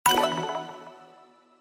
menu-charts-click.ogg